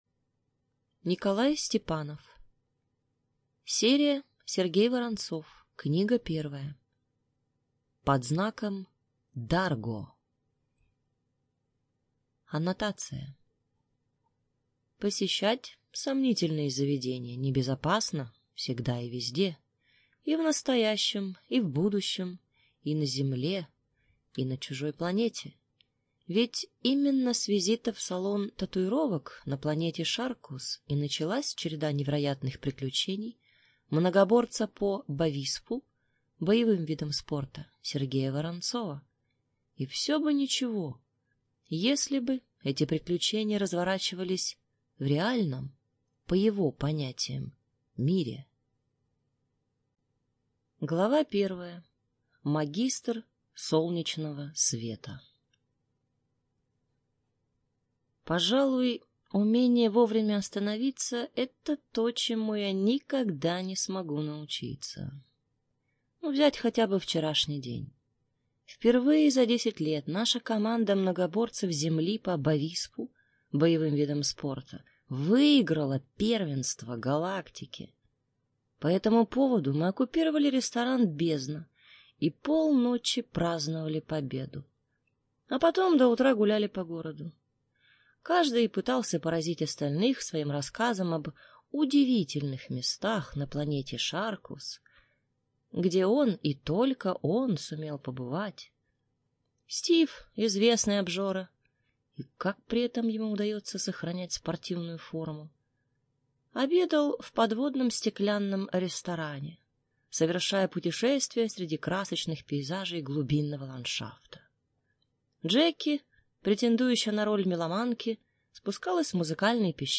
ЖанрФантастика, Фэнтези